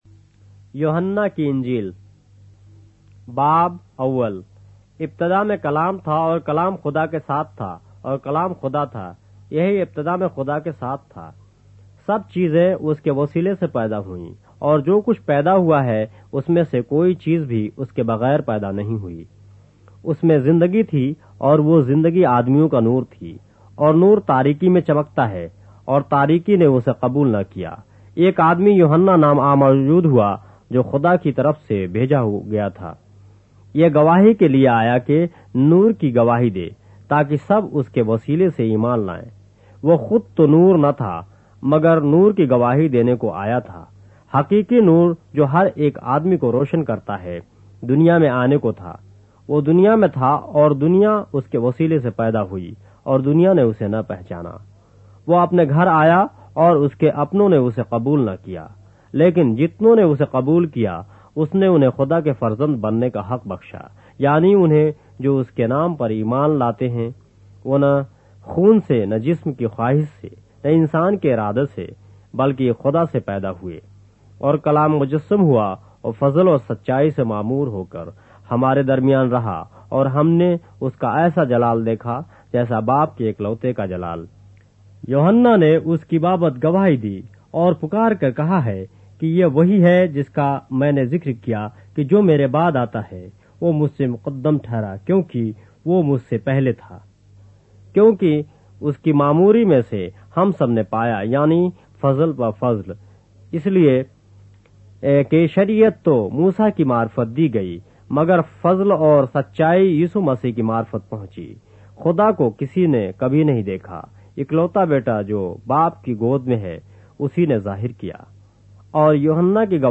اردو بائبل کے باب - آڈیو روایت کے ساتھ - John, chapter 1 of the Holy Bible in Urdu